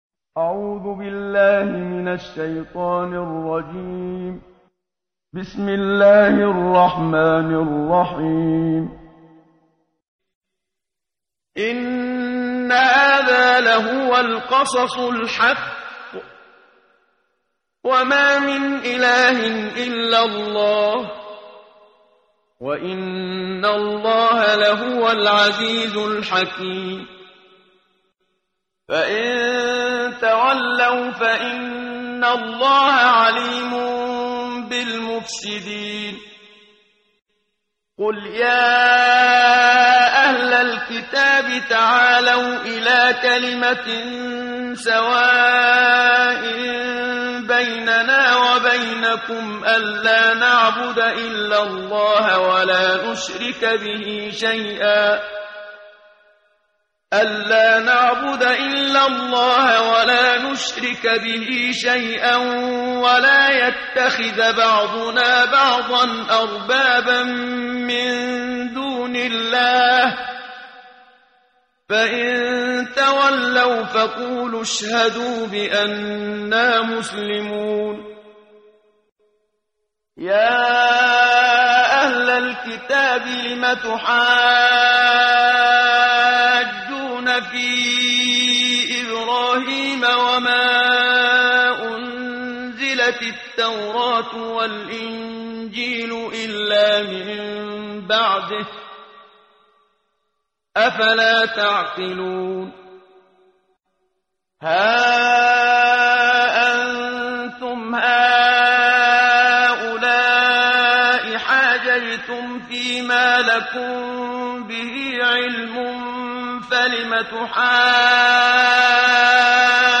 قرائت قرآن کریم ، صفحه 58، سوره مبارکه آلِ عِمرَان آیه 62 تا 70 با صدای استاد صدیق منشاوی.